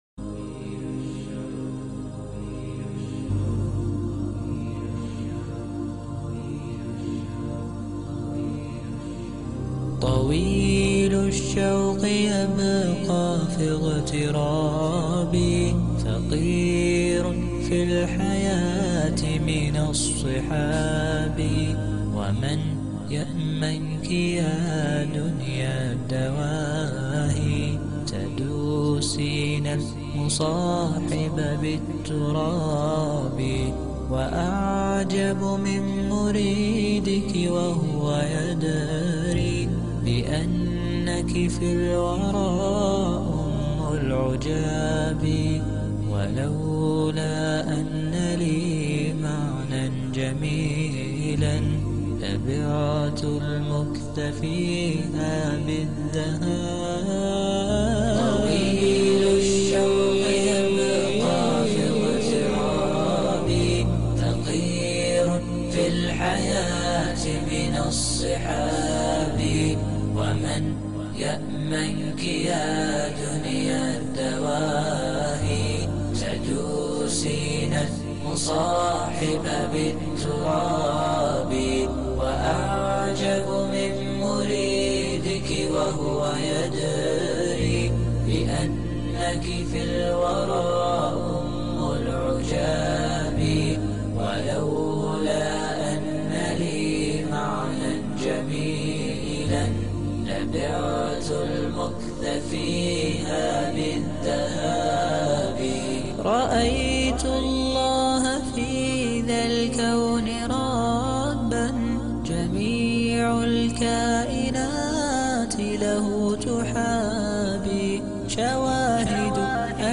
nashid_dolgaya_toska.mp3